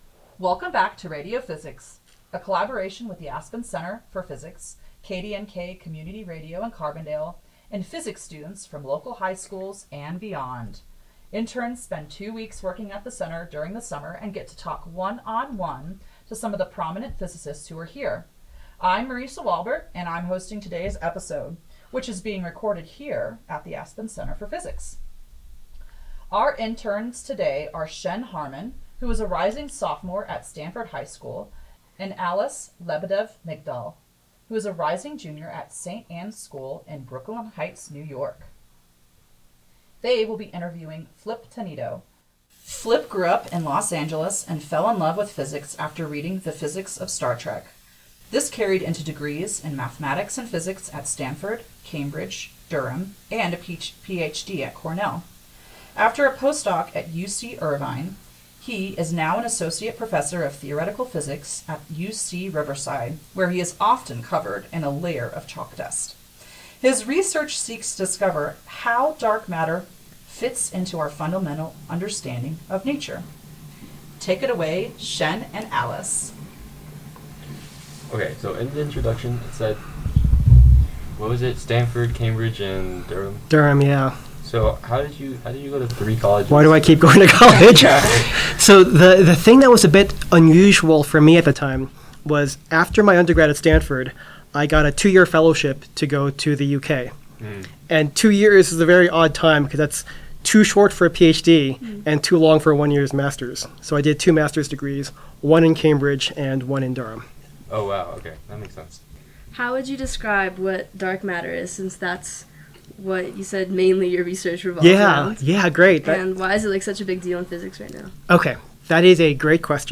interviewed by interns